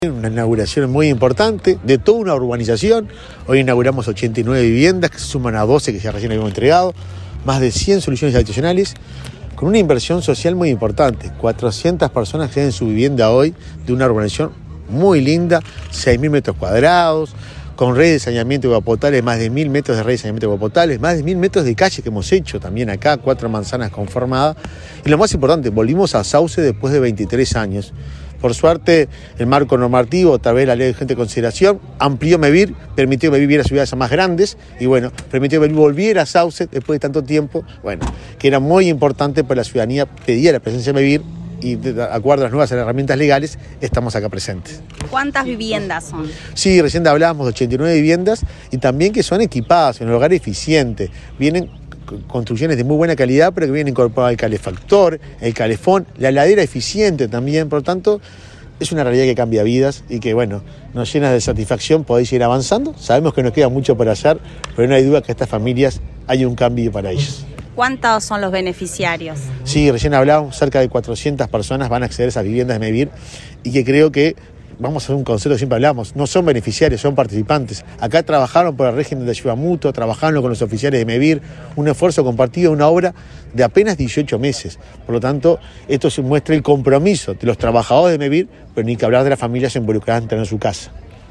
Entrevista al presidente de Mevir, Juan Pablo Delgado
Entrevista al presidente de Mevir, Juan Pablo Delgado 26/10/2023 Compartir Facebook X Copiar enlace WhatsApp LinkedIn El presidente de Mevir, Juan Pablo Delgado, dialogó con Comunicación Presidencial, antes de participar del acto de inauguración de 89 viviendas, en la localidad de Sauce, departamento de Canelones.